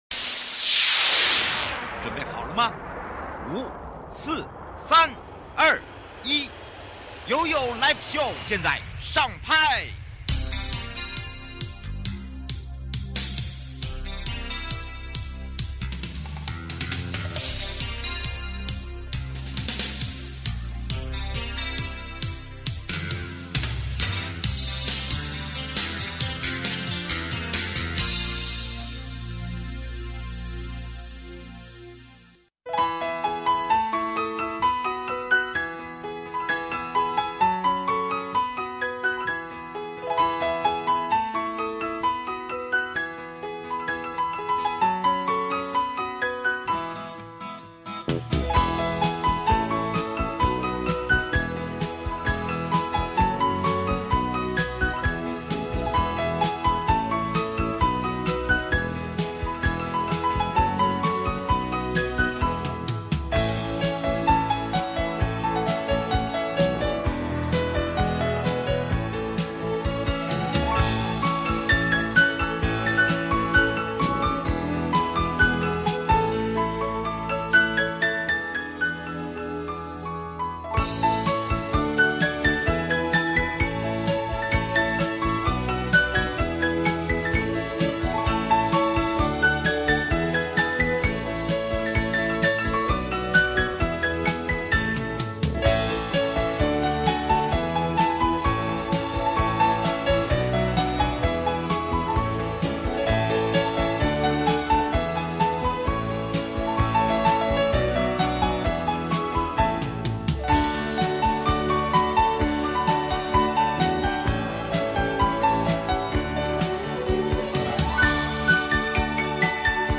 受訪者： 1.阿里山管理處洪維新處長